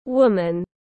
Người phụ nữ tiếng anh gọi là woman, phiên âm tiếng anh đọc là /ˈwʊm.ən/.
Woman /ˈwʊm.ən/
Woman.mp3